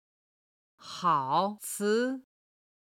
好兹　(hǎo cí)　美味しい。